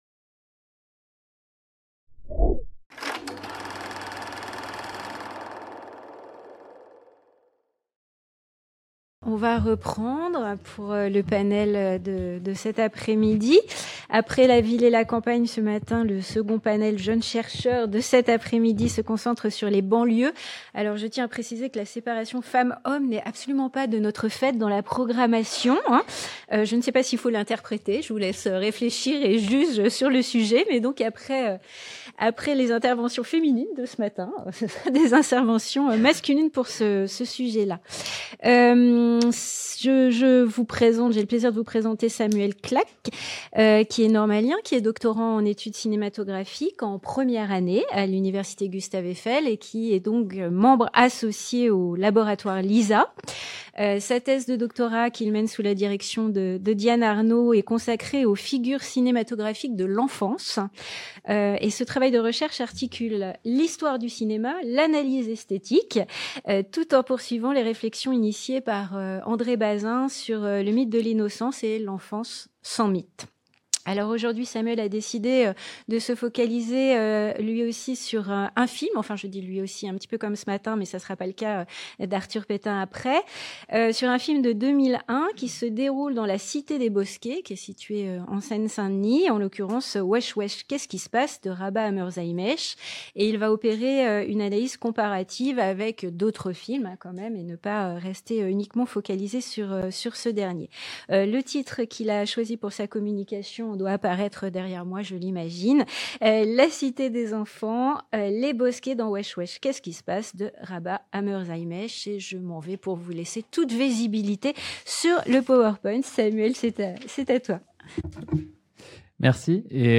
dans le cadre de la journée d'étude Écopoétique de la vie périurbaine au cinéma, qui s'est tenue le 27 janvier 2025 à la FMSH